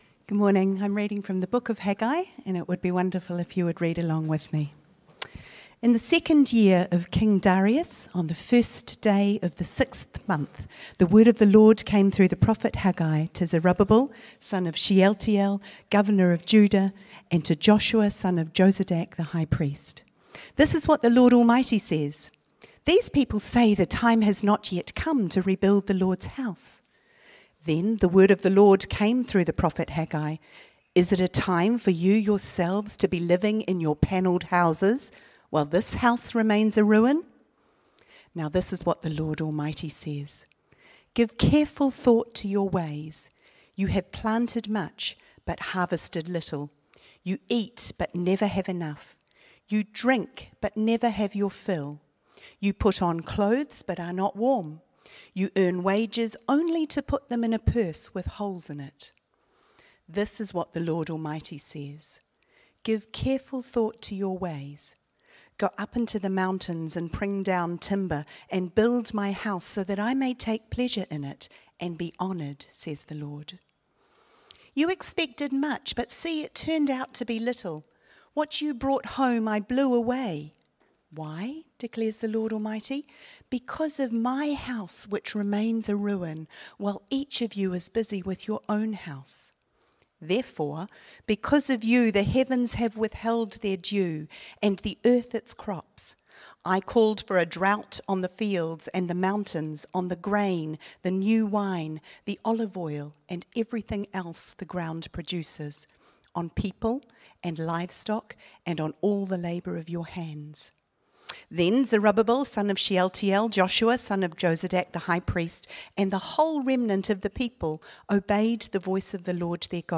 Sermons | Titirangi Baptist Church